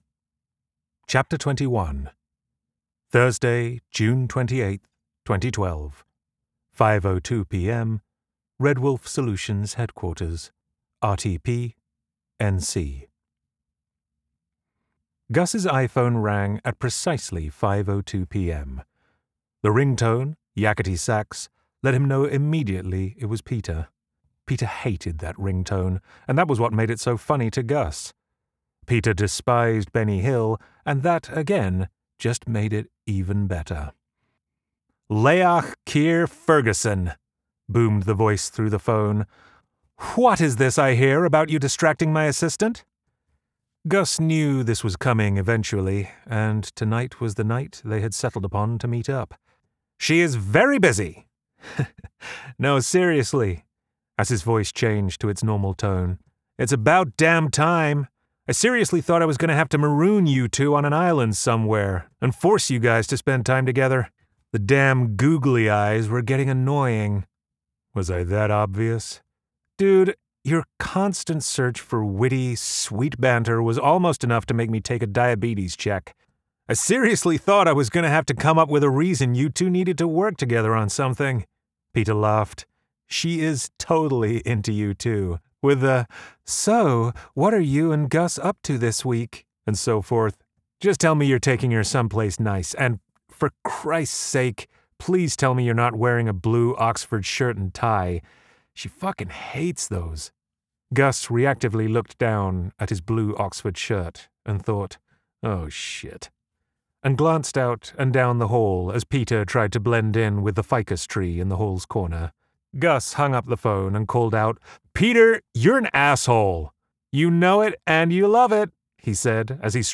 NEW!!! As of September 12th 2025 the audio-book version is on Audible!
This preview shows how a book at times tense can also be fun and real.